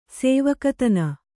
♪ sēvakatana